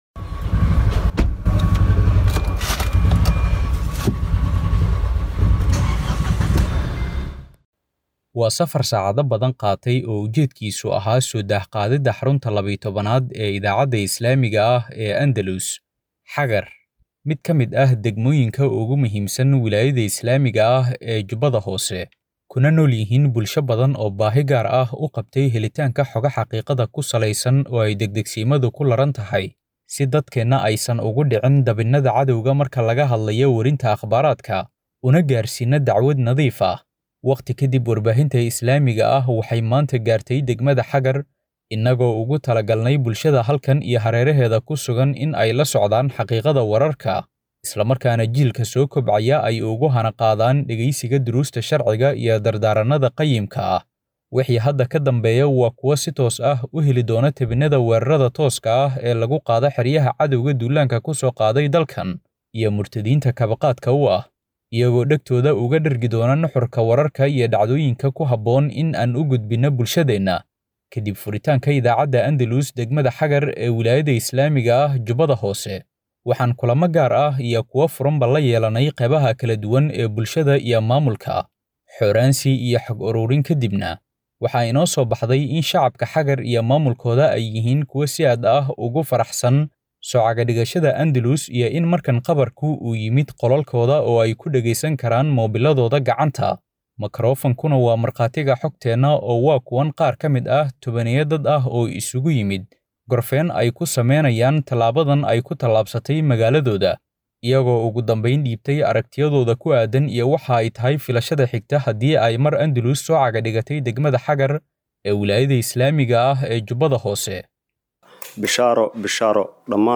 Halkan Ka Dhagayso Warbixinta Idaacadda Cusub Ee Xagar.